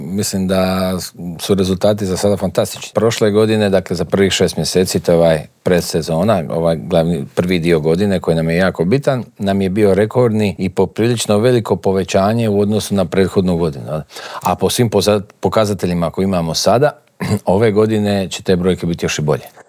O dosad ostvarenim rezultatima, očekivanjima od ljetne turističke sezone, ali i o cijenama smještaja te ugostiteljskih usluga razgovarali smo u Intervjuu tjedna Media servisa s ministriom turizma i sporta Tončijem Glavinom.